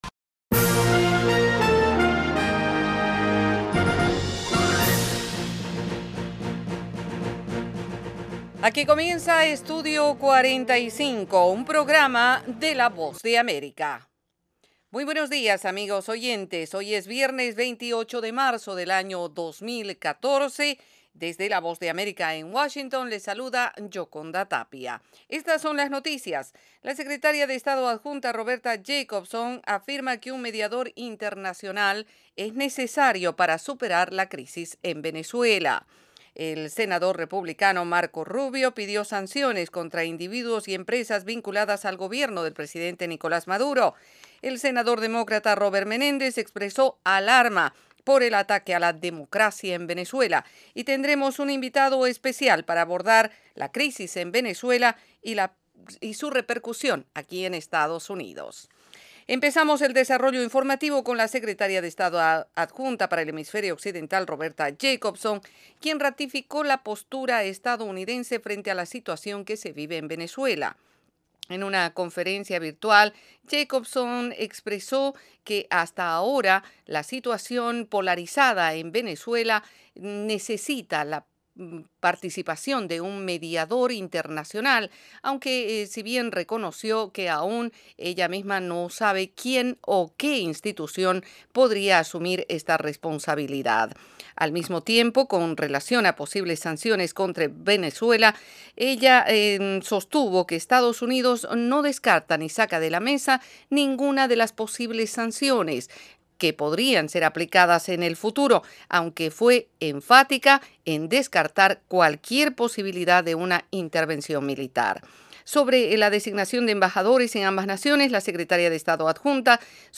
El programa ofrece -en 30 minutos- la actualidad noticiosa de Estados Unidos con el acontecer más relevante en América Latina y el resto del mundo. Estudio 45 se transmite de lunes a viernes, de 8:00 a 8:30 de la mañana, hora de Washington, vía satélite